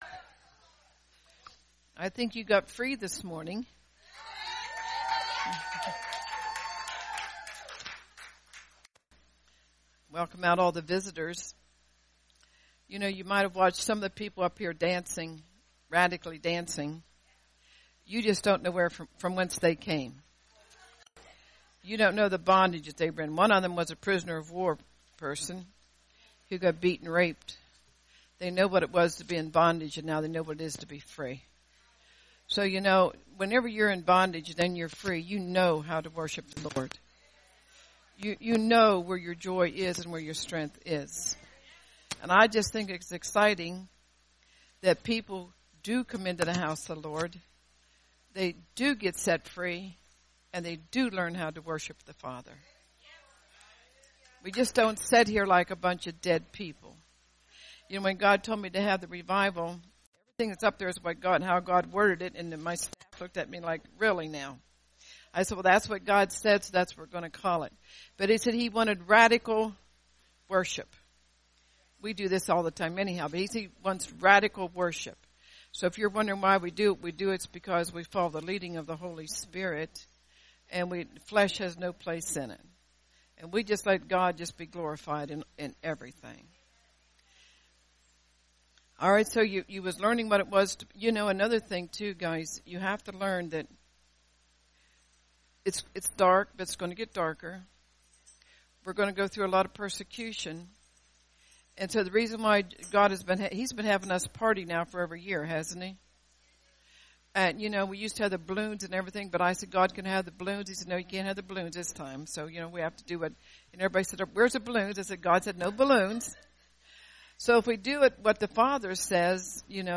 Great Miracle Service Revival